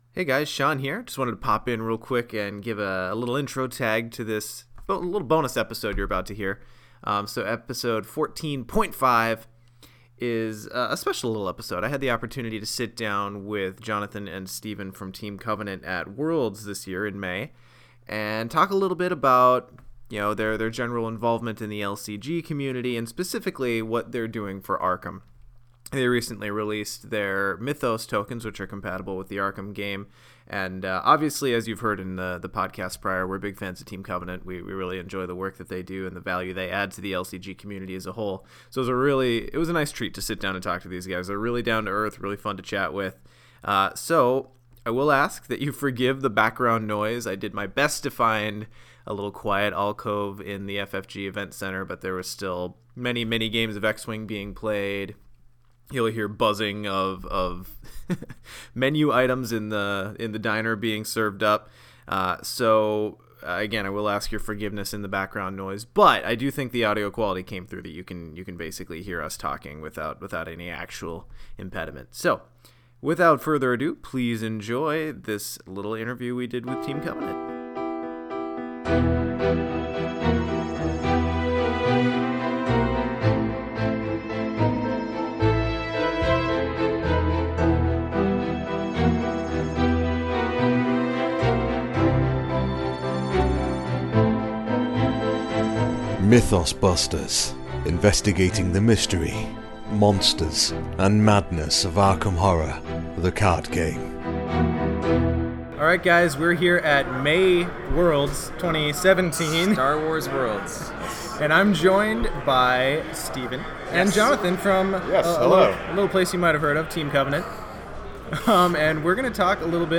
Mythos Bonus: Team Covenant Interview